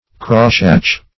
Crosshatch \Cross"hatch`\ (-h?ch`; 224), v. t.